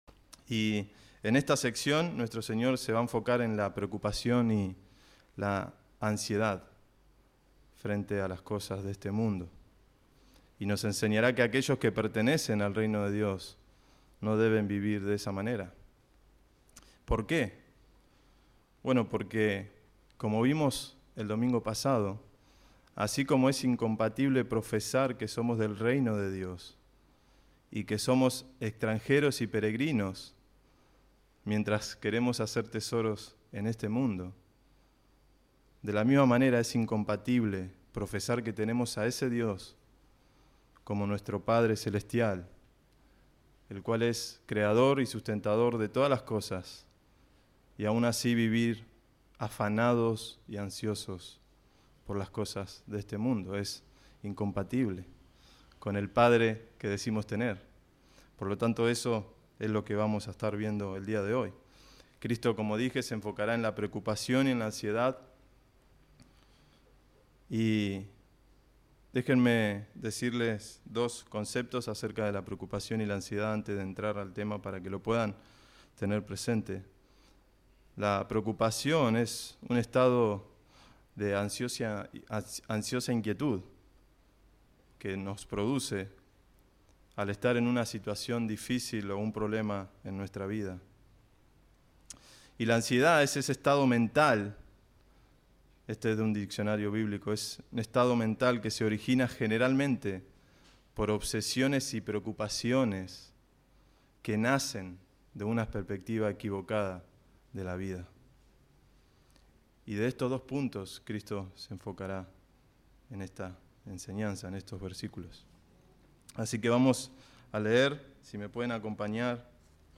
enero 3, 2021 Sermón ¿Dónde está puesta tu confianza?